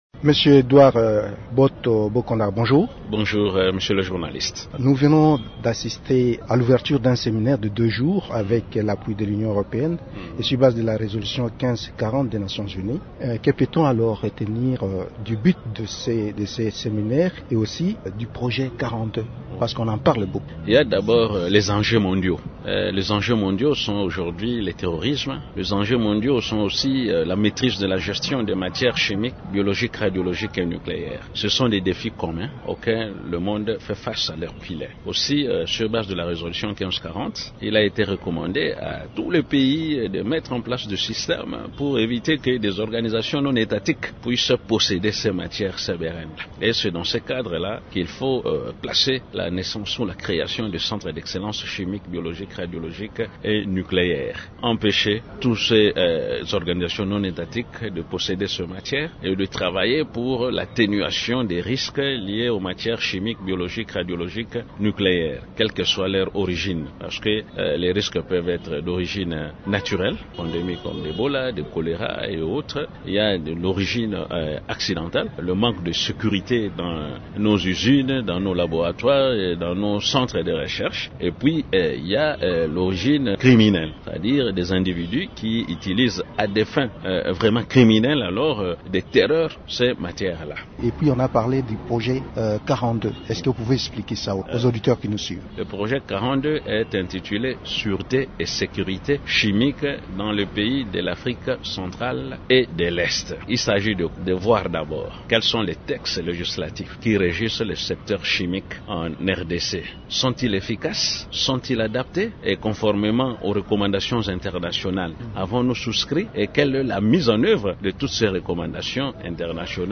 L'invité du jour, Émissions / retrait, M23, Ndeye Khady Lo, mandat, Monusco, résolution